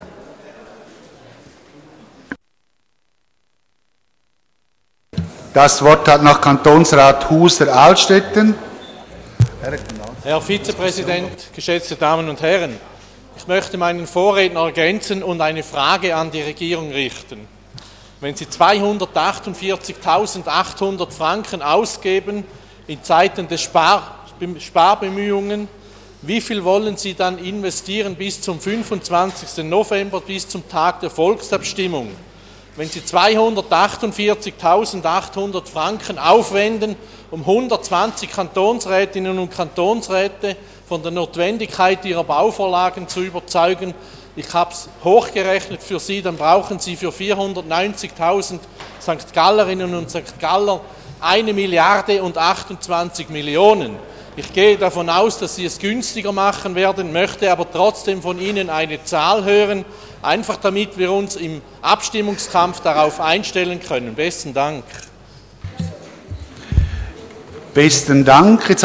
24.2.2014Wortmeldung
Session des Kantonsrates vom 24. und 25. Februar 2014